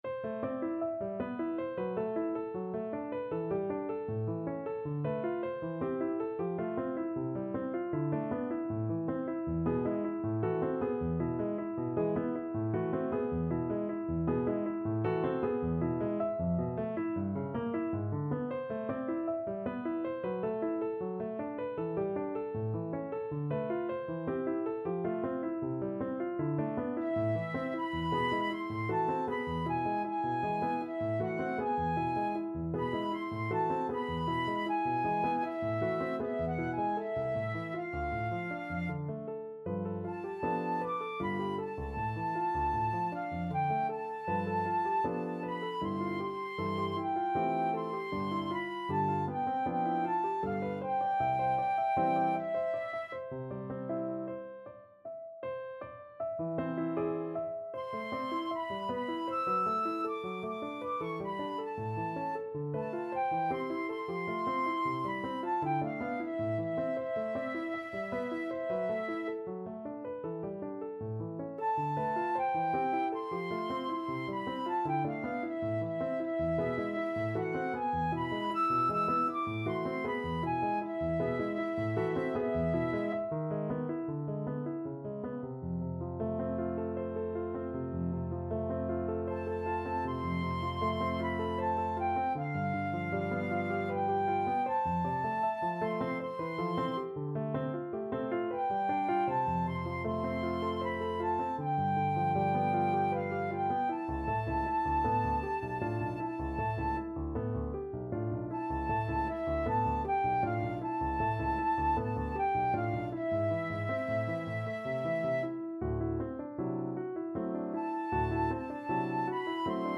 Flute version
3/4 (View more 3/4 Music)
Flute  (View more Intermediate Flute Music)
Classical (View more Classical Flute Music)